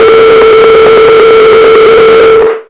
RADIOFX  4-L.wav